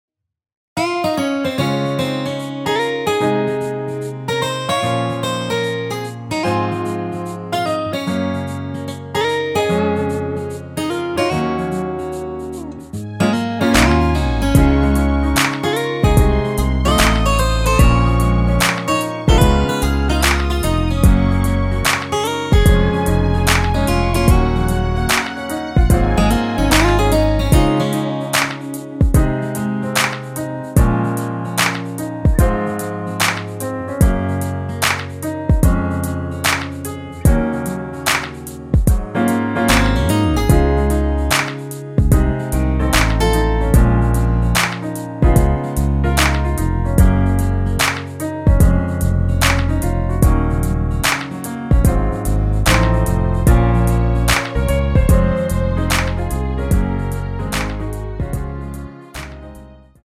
Db
◈ 곡명 옆 (-1)은 반음 내림, (+1)은 반음 올림 입니다.
앞부분30초, 뒷부분30초씩 편집해서 올려 드리고 있습니다.